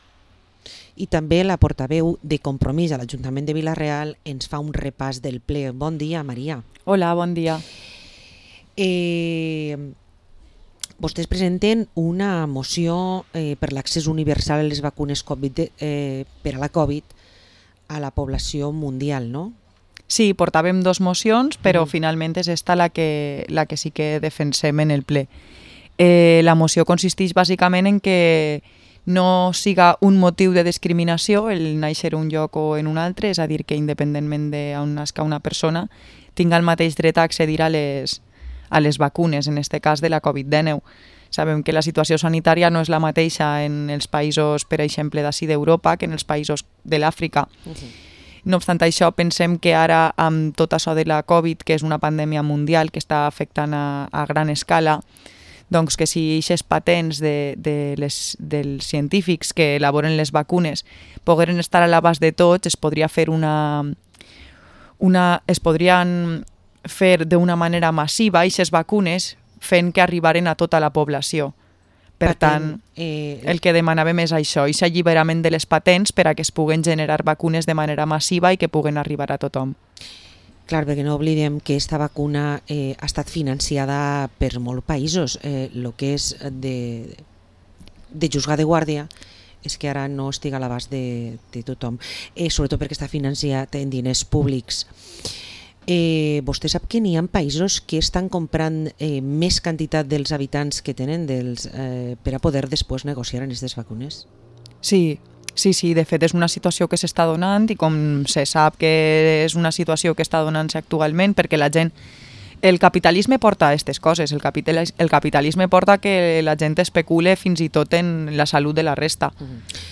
Entrevista a la concejala de Compromís per Vila-real, María Fajardo